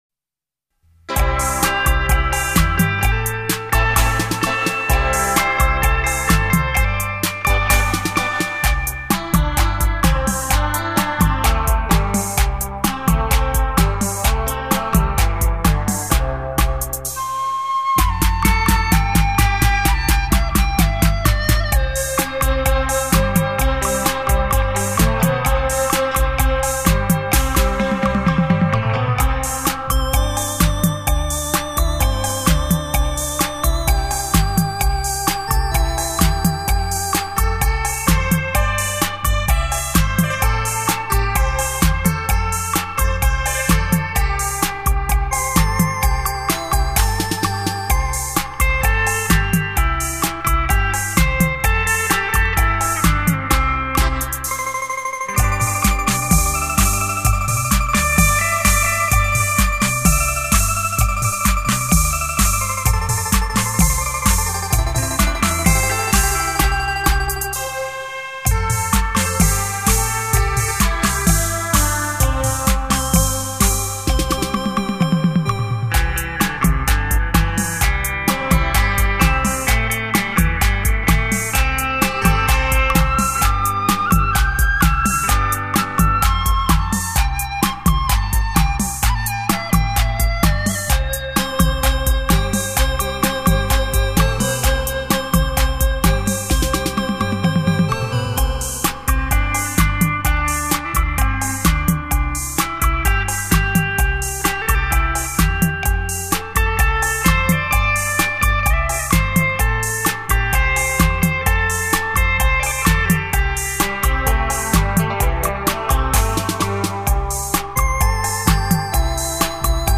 专辑类型：电子琴音乐
编曲演奏原音重现·超立体现场演奏
曲曲精選 曲曲動聽，电子琴音域较宽，和声丰富，
精心打造完美电子音乐，立體效果 環繞身歷聲 超魅力出擊，
采样格式　　　 : 44.100 Hz; 16 Bit; 立体声